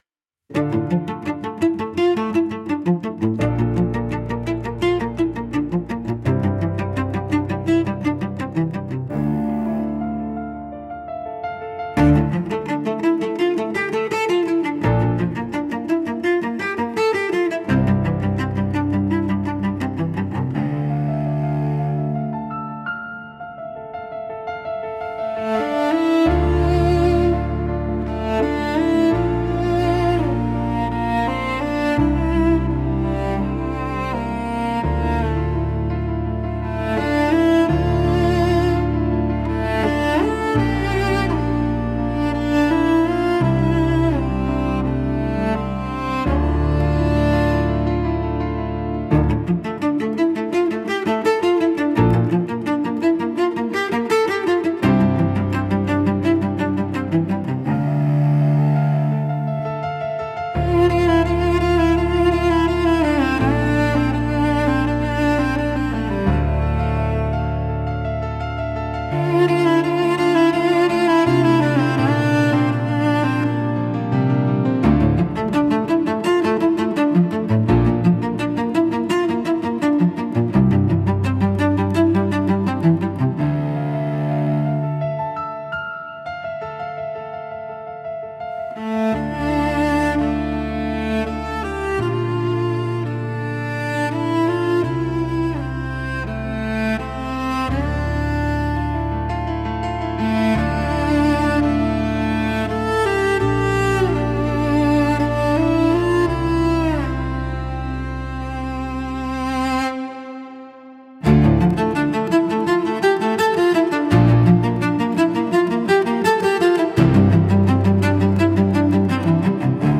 música e arranjo: IA) instrumental 6